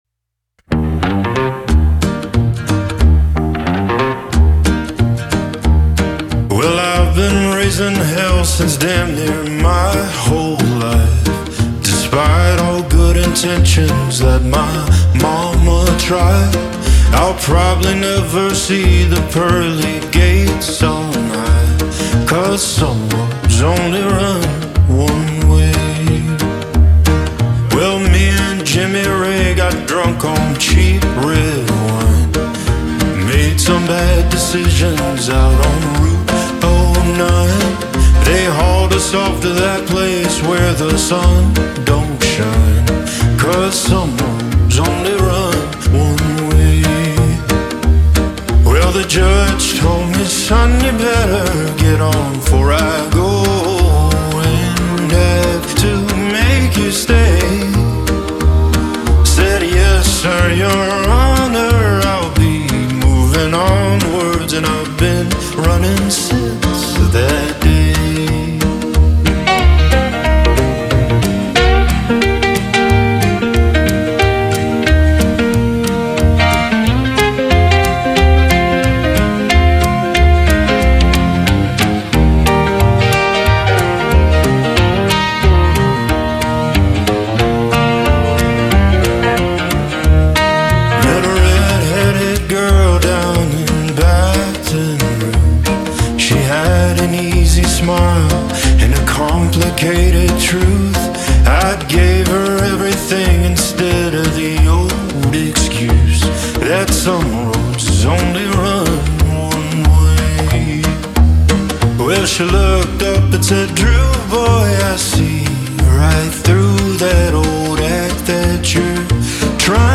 Pop
Eb Major